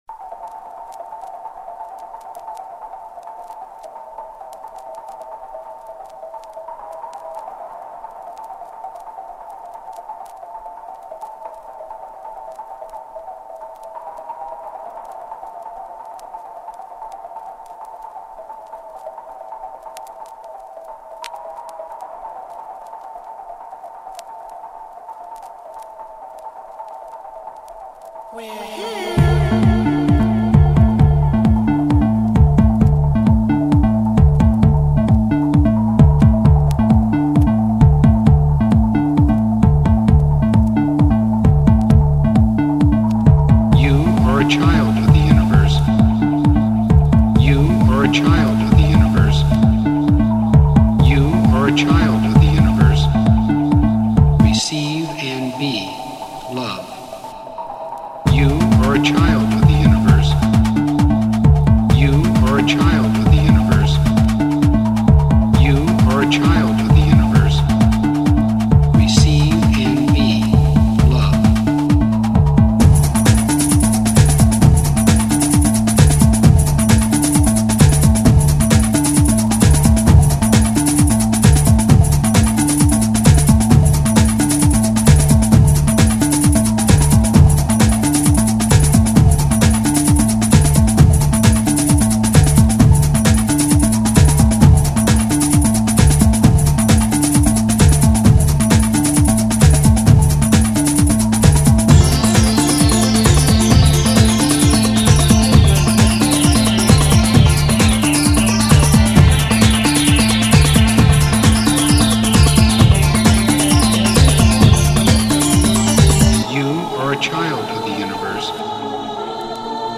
Styl: jungle, dnb, breakbeat, dubstep
Oldschoolový dnb podcast